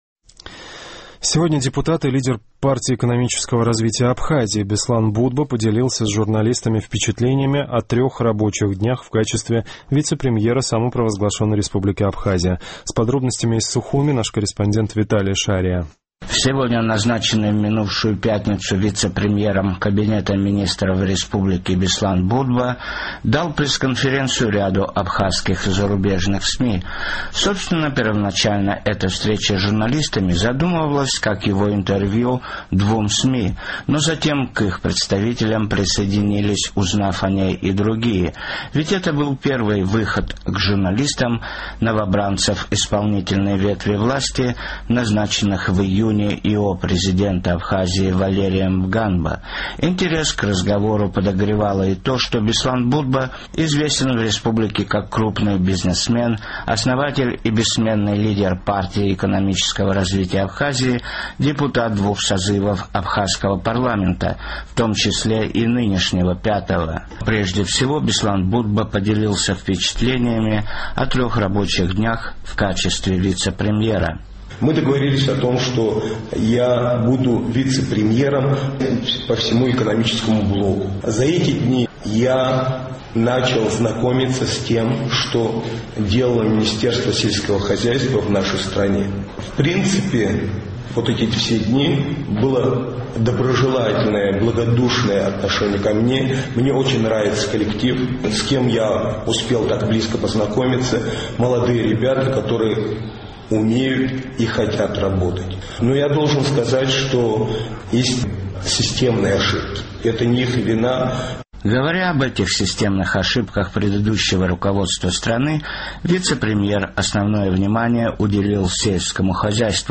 Сегодня назначенный в минувшую пятницу вице-премьером кабинета министров РА Беслан Бутба дал пресс-конференцию ряду абхазских и зарубежных СМИ.